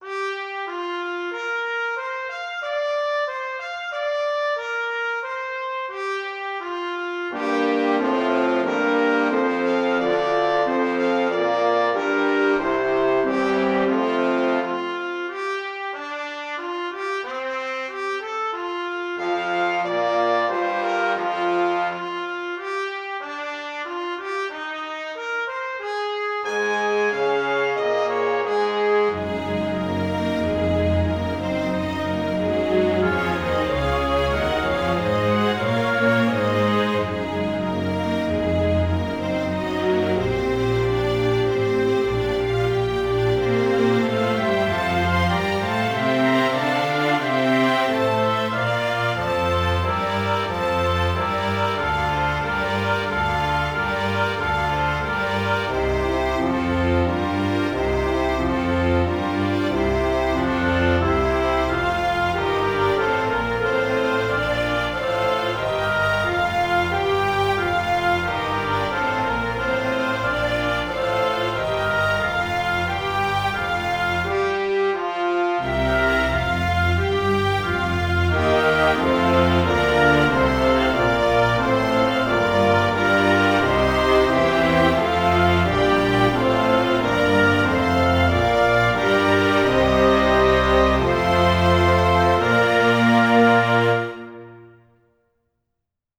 Finally there's an excerpt from Promenade from Pictures at an Exhibition by Mussorgsky, arranged for an orchestra.
No processing of any kind, all the reverb is present in the samples.